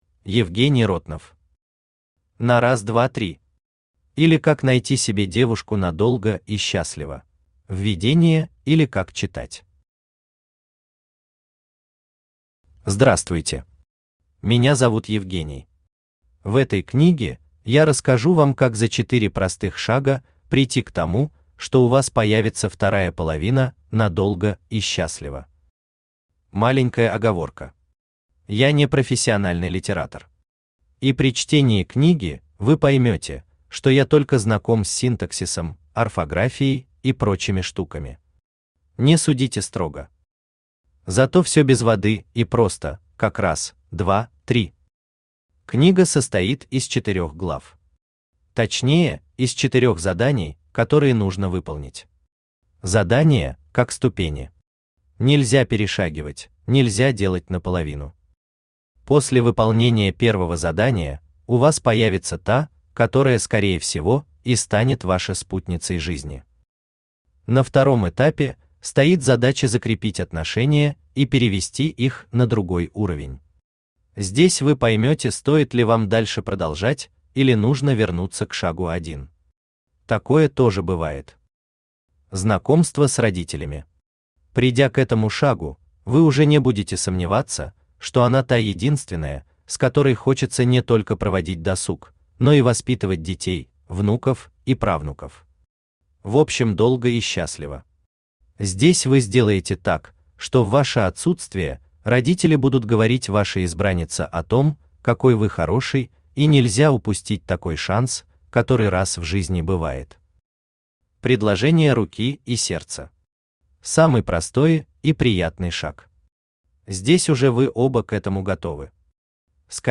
Автор Евгений Сергеевич Ротнов Читает аудиокнигу Авточтец ЛитРес.